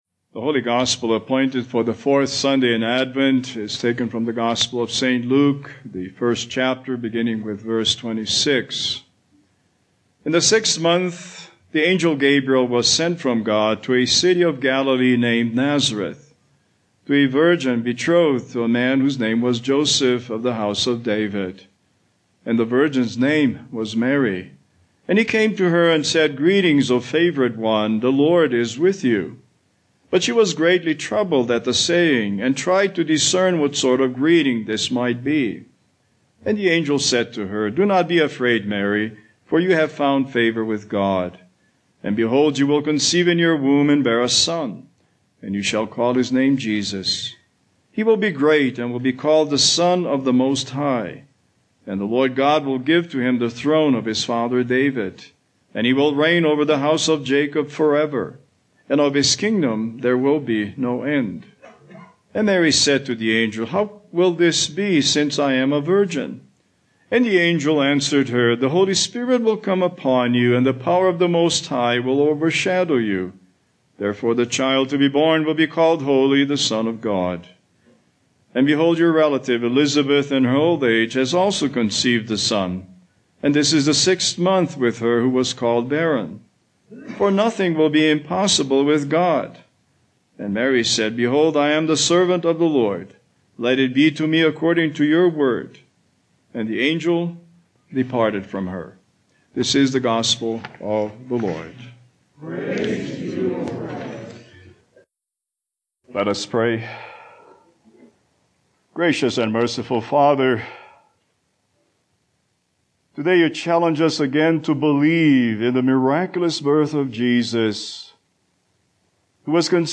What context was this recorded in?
Passage: Luke 1:26-38 Service Type: Christmas Eve